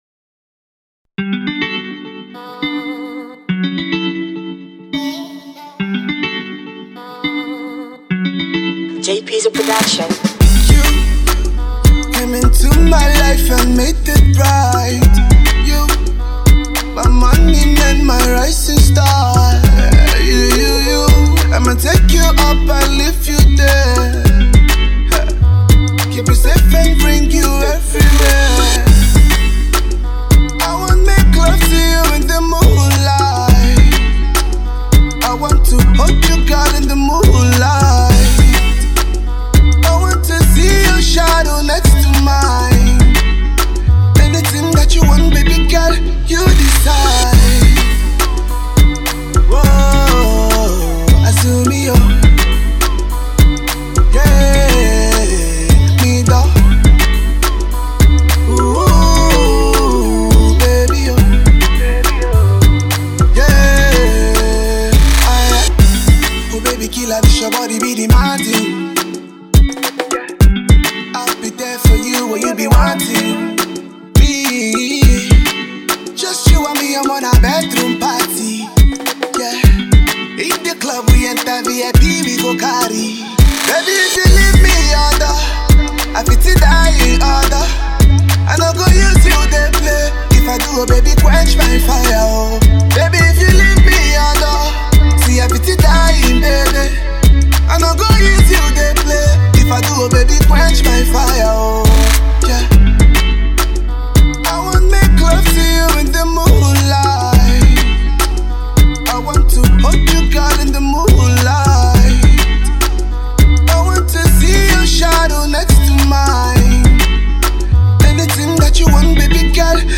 afropop